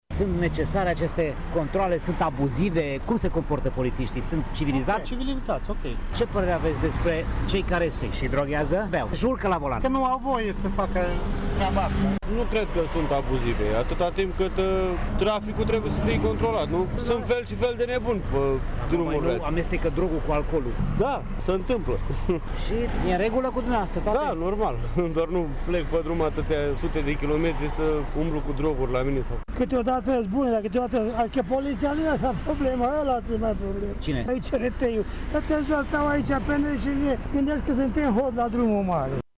Șoferii apreciază ca fiind utile aceste controale: